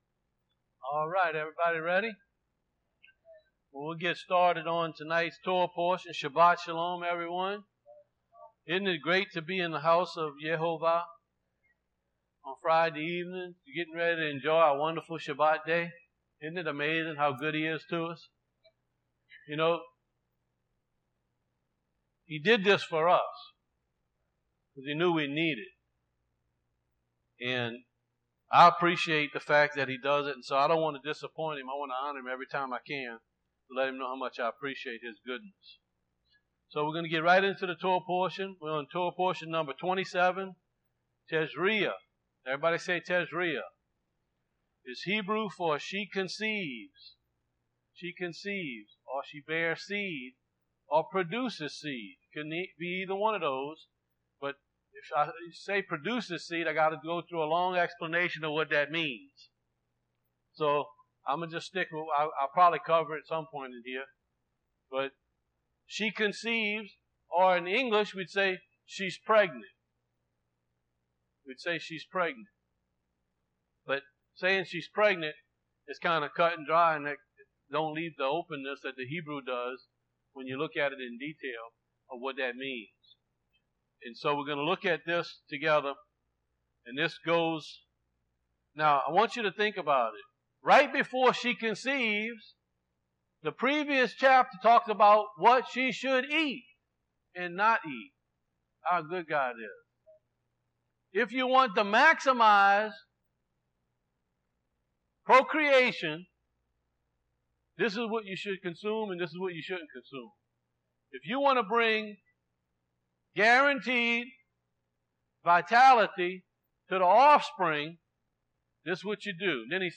Torah Teachings – Tazria Part 1